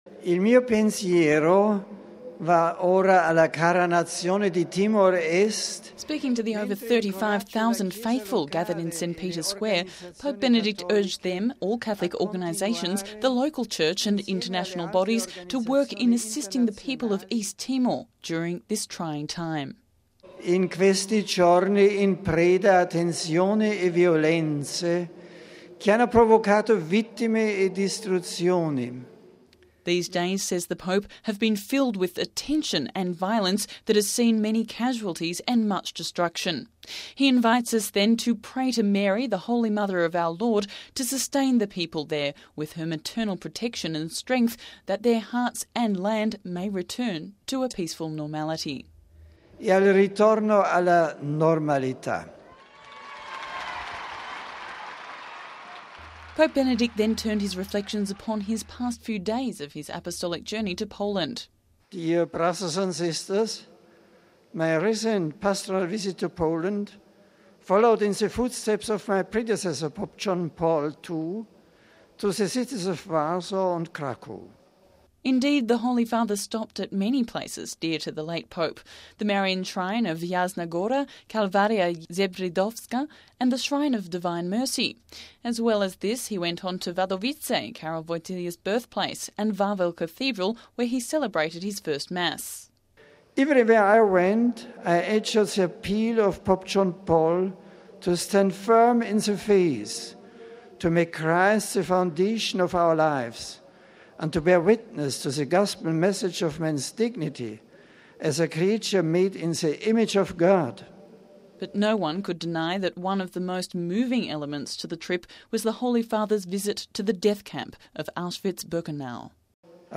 (31 May 06 - RV) At his general audience this morning, Pope Benedict XVI turned his thoughts to East Timor and launched an appeal for the international community to join together in solidarity for this terror-stricken nation.